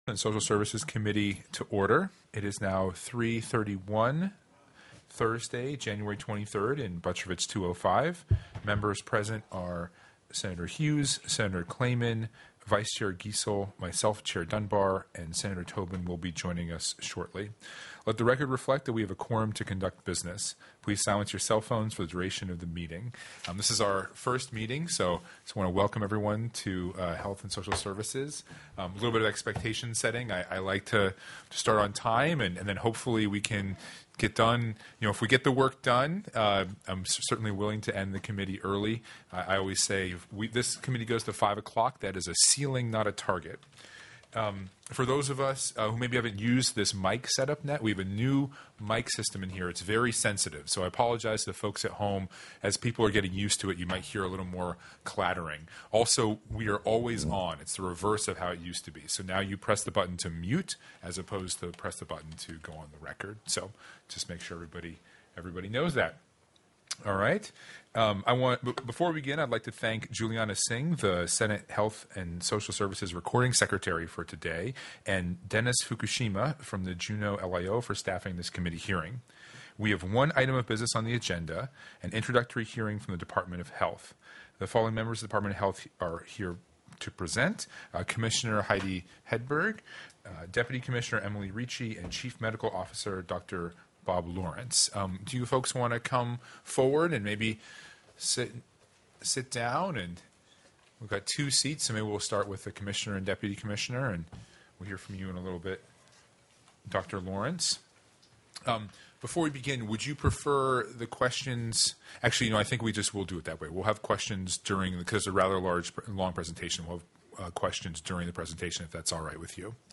01/23/2025 03:30 PM Senate HEALTH & SOCIAL SERVICES
The audio recordings are captured by our records offices as the official record of the meeting and will have more accurate timestamps.